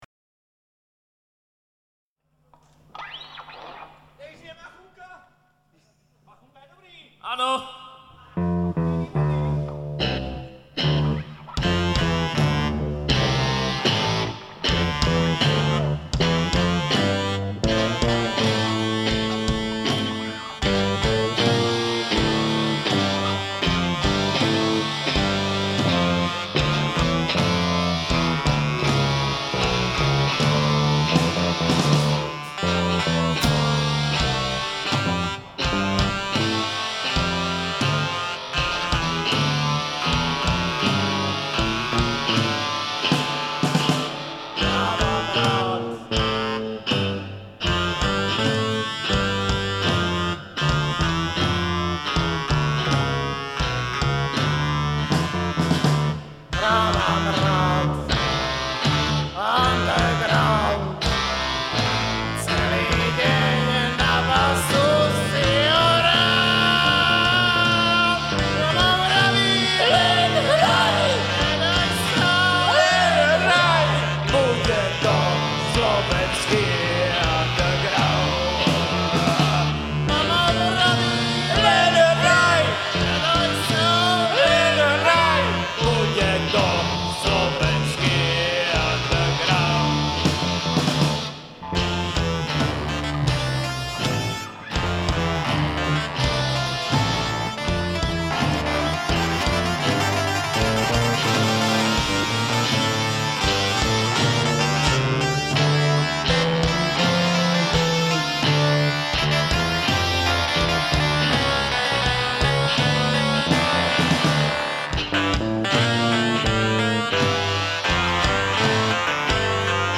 Nahráte: Live Kino Hviezda Trenčín 16.12.2000